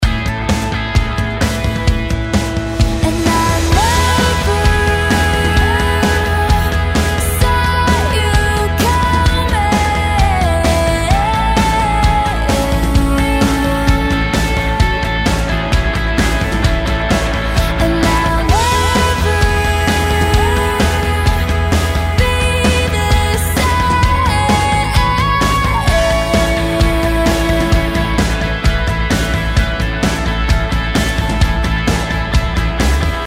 • Качество: 128, Stereo
поп
женский вокал
dance
Electronic
бодрые